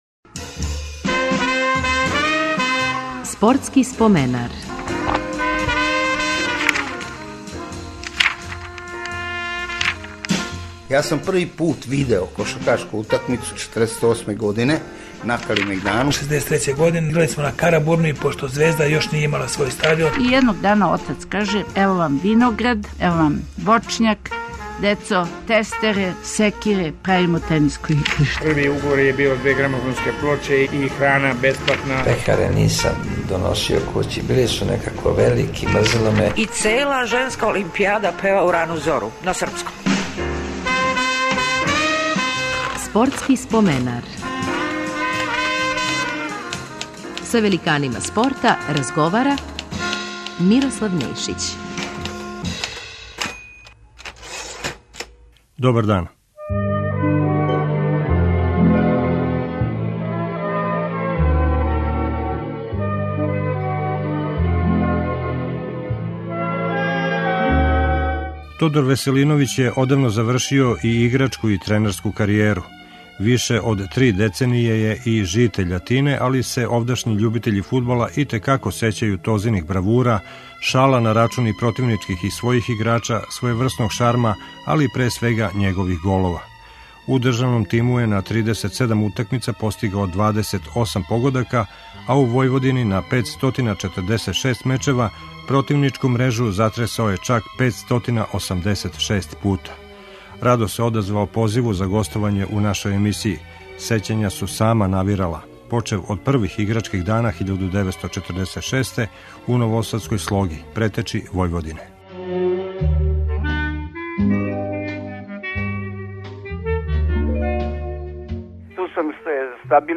Репризираћемо емисију у којој је гост Тодор Тоза Веселиновић, један од највећих голгетера југословенског фудбала.